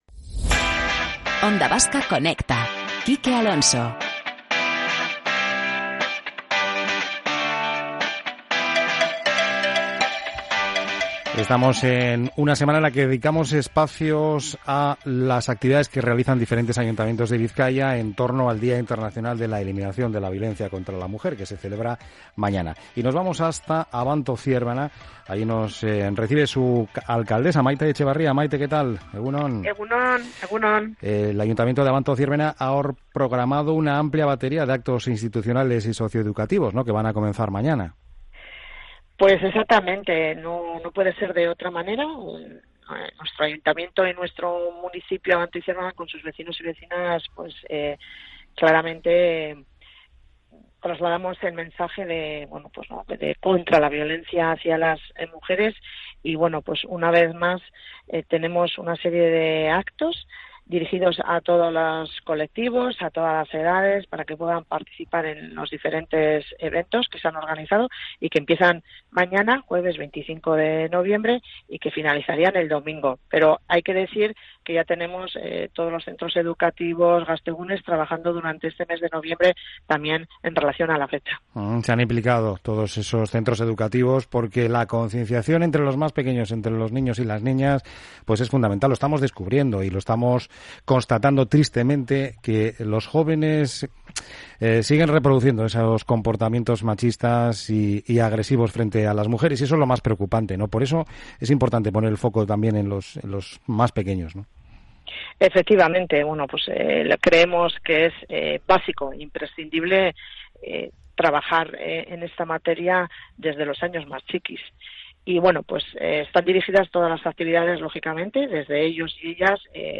La alcaldesa de Abanto Zierbena Maite Etxebarria nos ha detallado el programa de actos institucionales y socio educativos con motivo del Día Internacional de la Eliminación de la Violencia contra la Mujer, entre ellos la colocación de zapatos rojos en memoria de las mujeres víctimas de violencia